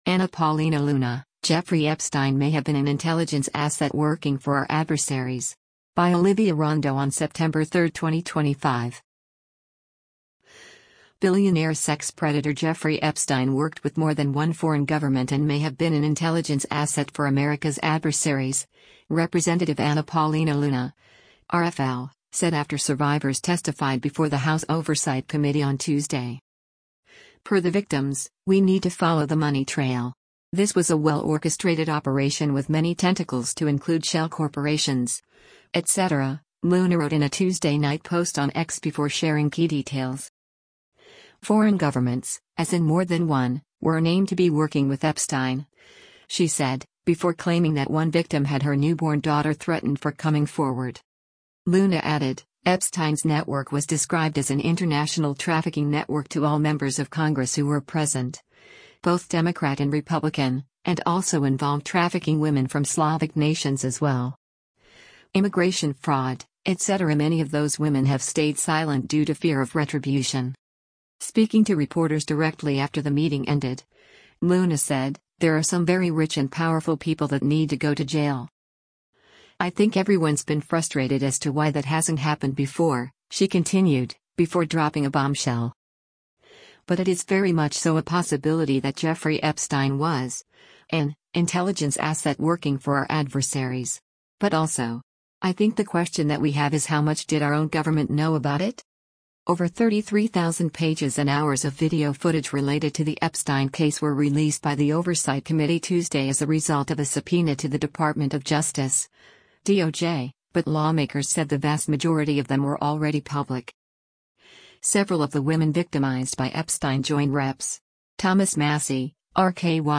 Speaking to reporters directly after the meeting ended, Luna said, “There are some very rich and powerful people that need to go to jail.”